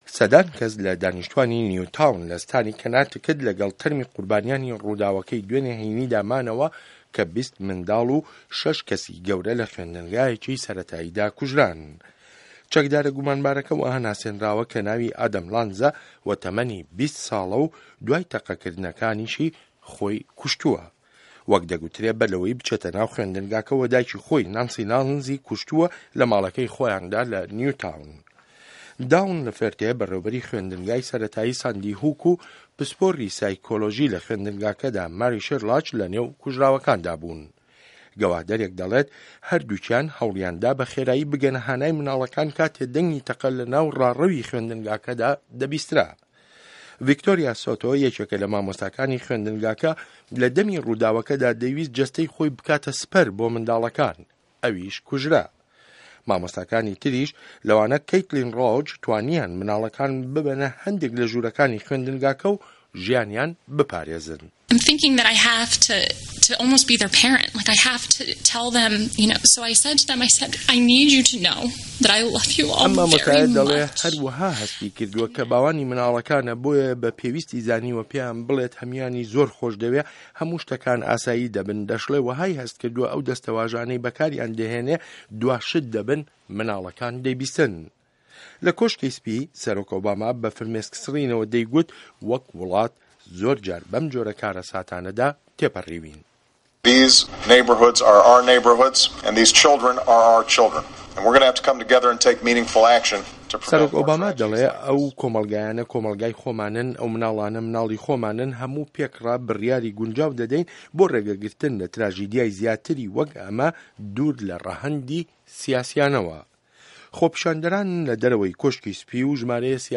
ڕاپۆرت له‌سه‌ر ته‌قه‌کردنه‌که‌ی نیوتاون